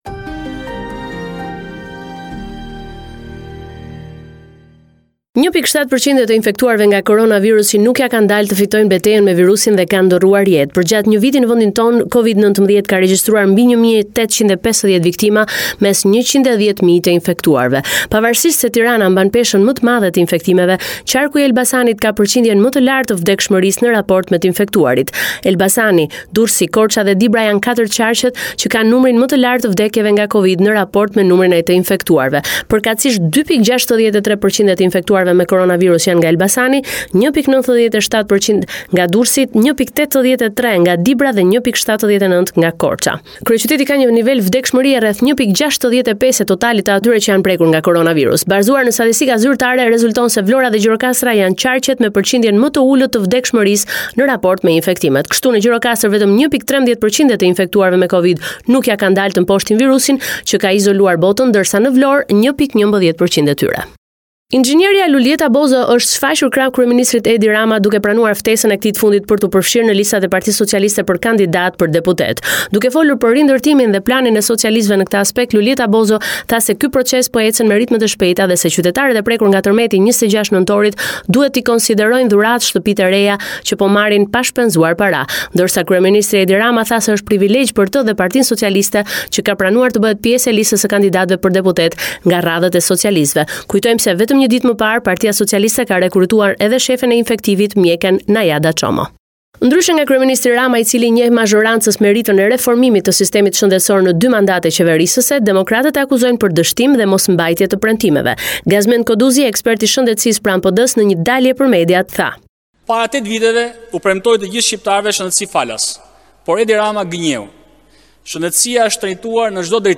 This is a report summarising the latest developments in news and current affairs in Albania.